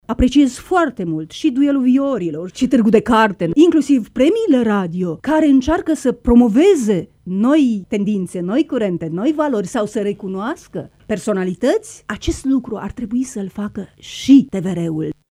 Senatorul PSD, Gabriela Creţu, membru în Comisia pentru Cultură, a declarat la o dezbatere la Radio România Iaşi că una dintre modificările propuse la legea de funcţionare a societăţilor publice de radio şi televiziune de separare a funcţiilor de preşedinte al Consiliului de Administraţie de cea de director general ar asigura un control mai bun asupra managementului celor două instituţii.